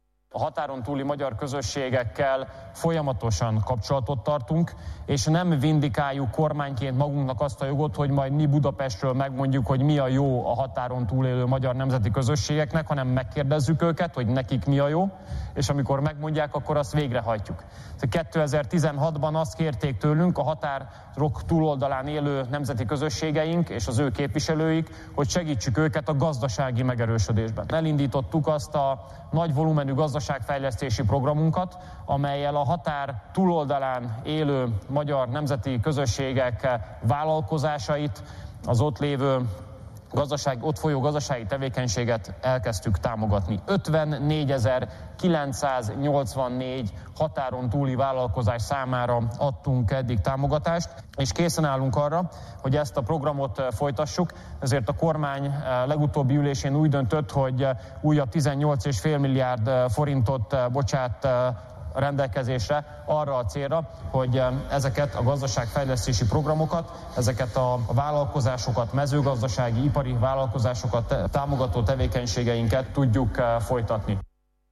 Folytatja a magyar kormány a határon túli magyar közösségeket célzó gazdaságfejlesztési programokat. Ezt Szijjártó Péter, Magyarország külgazdasági és külügyminisztere jelentette be ma az Országgyűlés előtt. Szijjártó Pétert hallják.